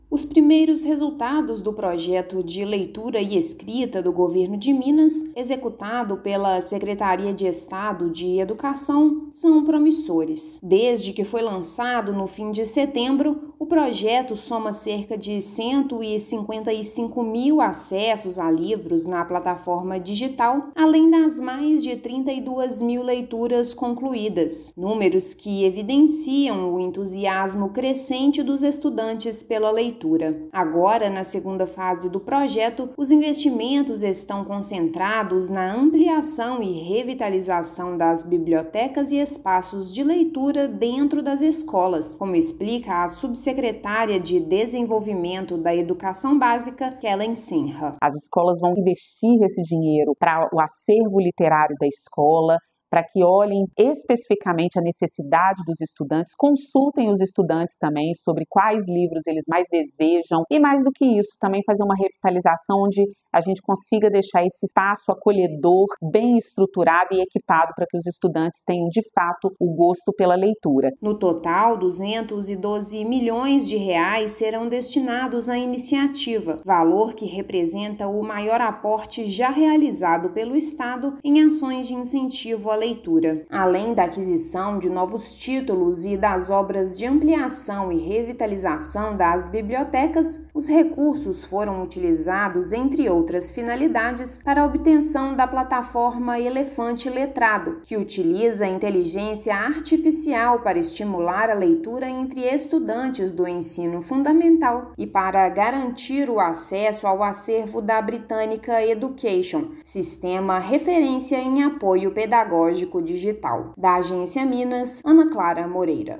Iniciativa envolve a revitalização de bibliotecas em 3,4 mil escolas públicas e a criação de um ambiente que promova o prazer pela leitura e a escrita entre os estudantes. Ouça matéria de rádio.